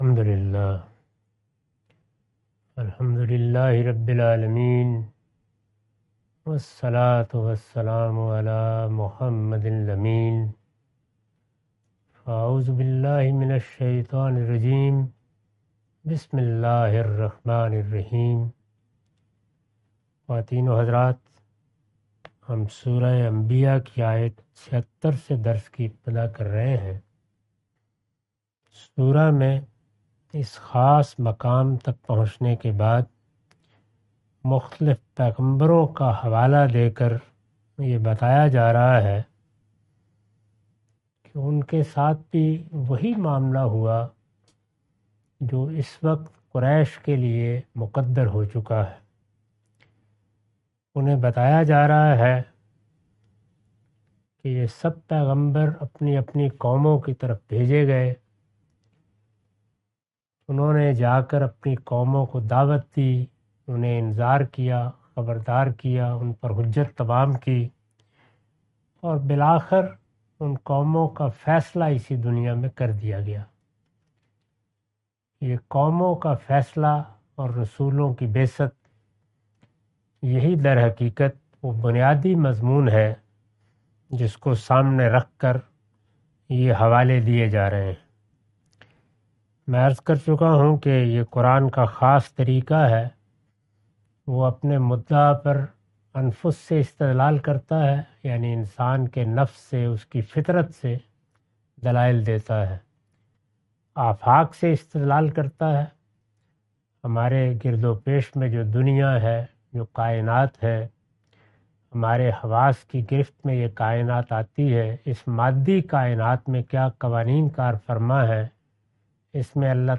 Surah Al-Anbiya A lecture of Tafseer-ul-Quran – Al-Bayan by Javed Ahmad Ghamidi. Commentary and explanation of verses 76-79.